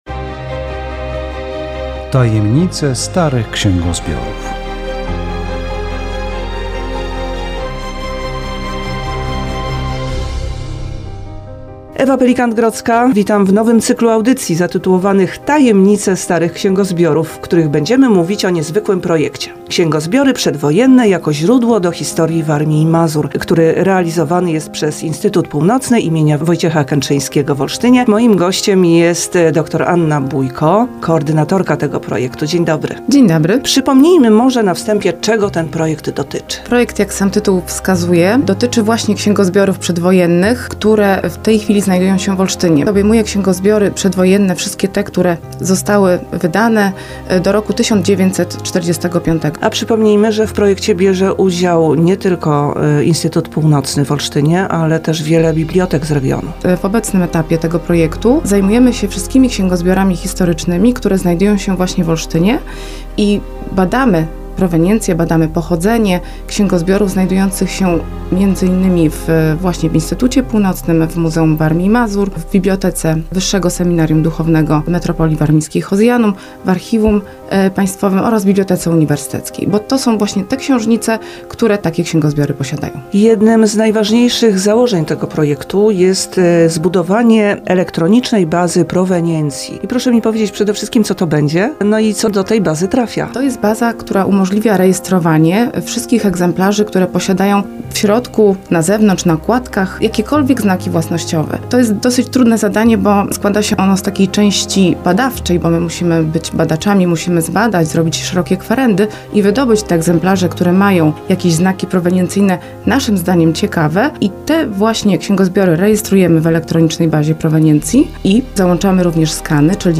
Audycja radiowa "Tajemnice starych księgozbiorów"